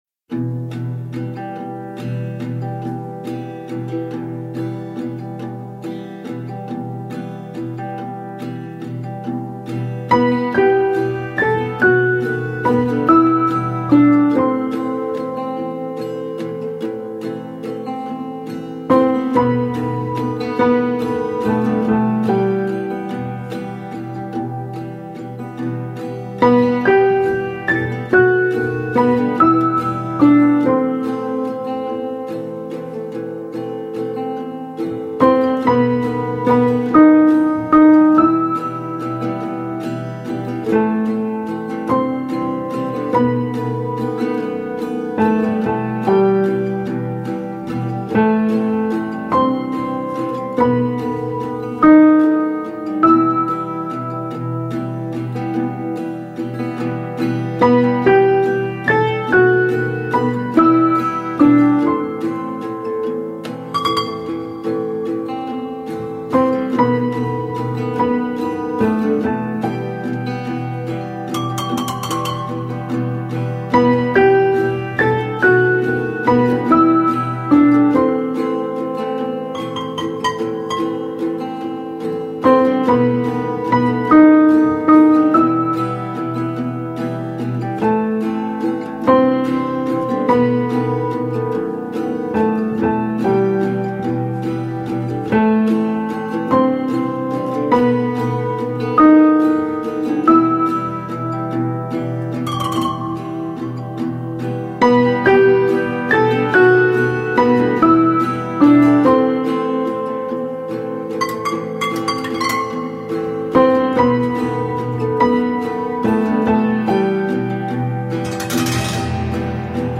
Soundtrack zum Film